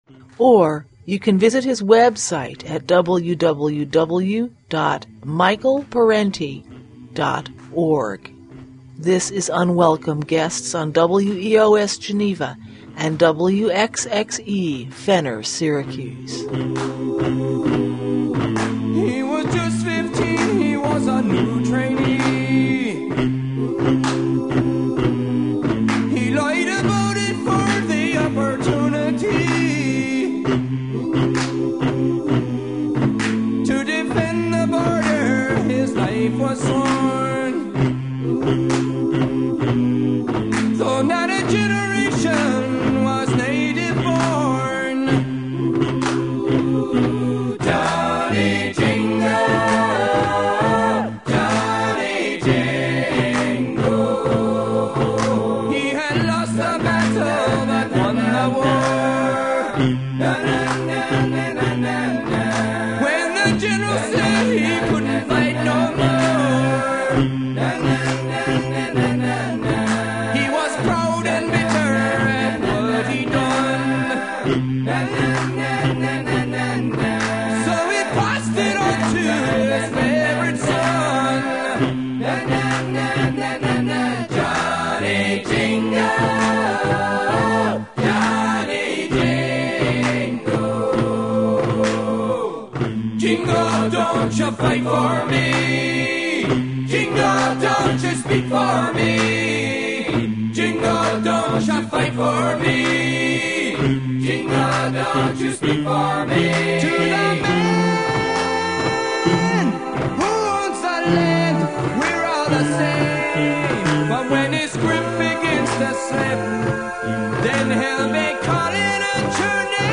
His lecture on "Superpatriotism" describes how the idea of 'patriotism' is used to manipulate people into becoming willing cannon fodder for the war machine, and who benefits from this.